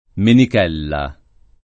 [ menik $ lla ]